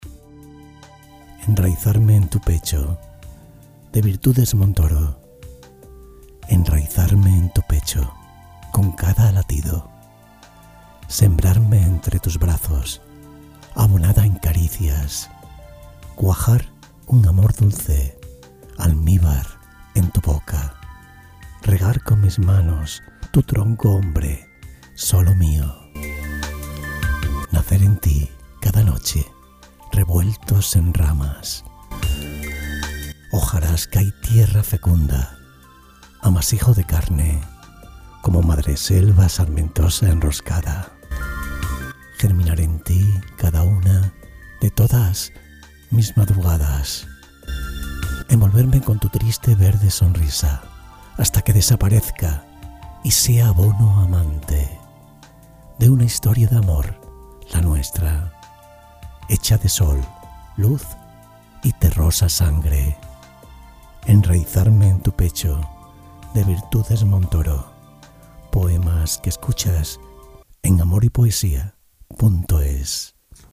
Poemas con voz. Poesías con voz. Poemas románticos con voz. Poesías románticas con voz